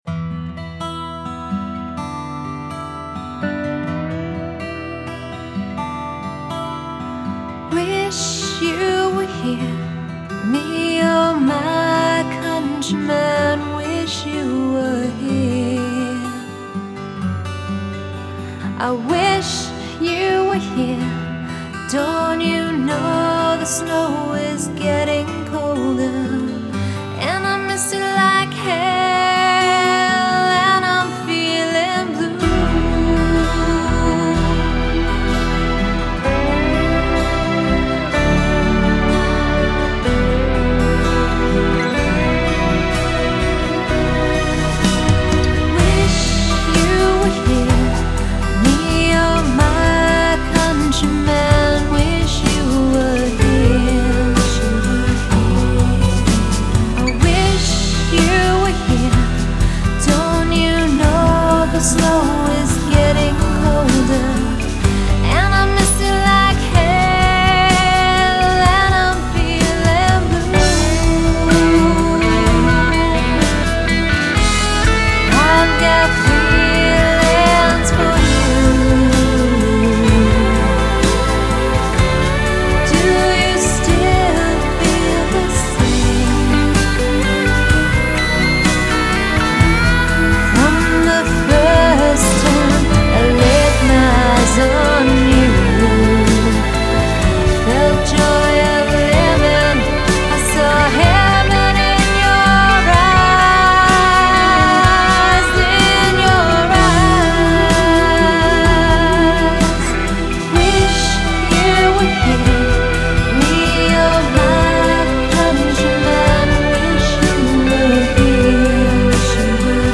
Жанр: фолк-рок
Genre: Folk, Rock